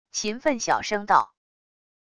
秦奋小声道wav音频